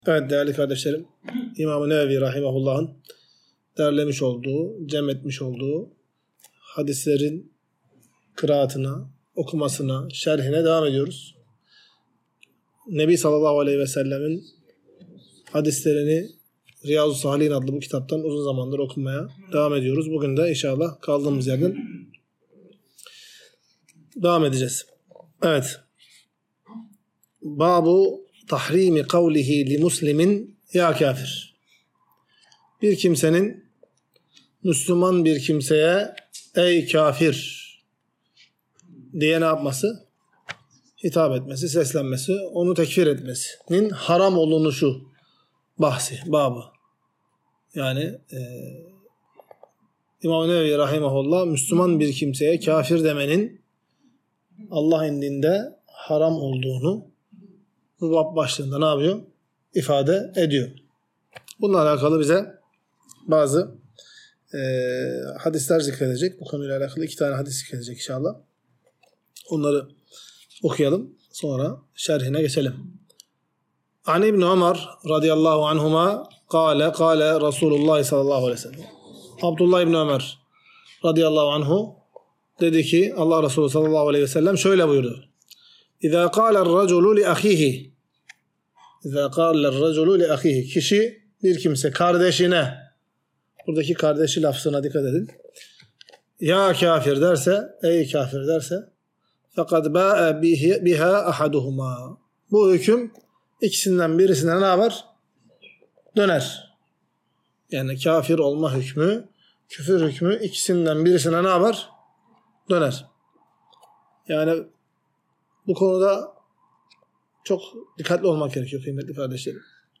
Ders - 72. Bölüm | İnsanın" Şu Yıldızın Hareketi Sayesinde Yağmura Kavuştuk" Demesinin Yasak Olduğu